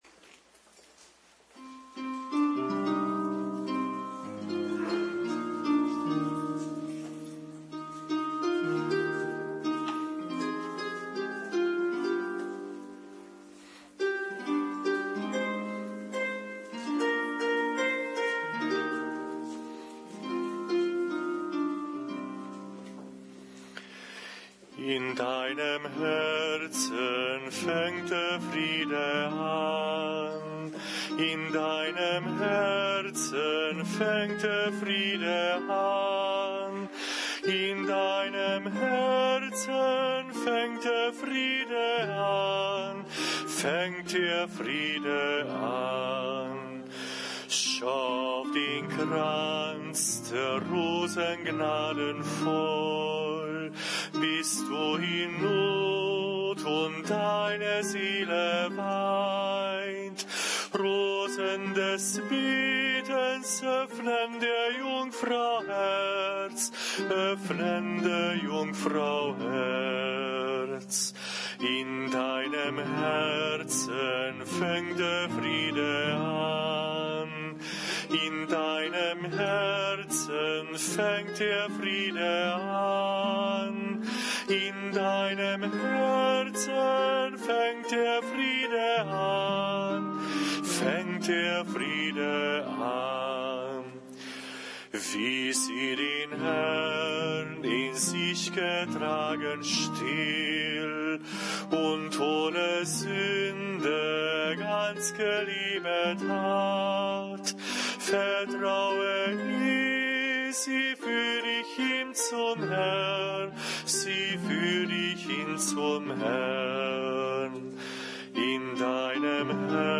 Die Musik und der Gesang der Engel waren leise und sanft.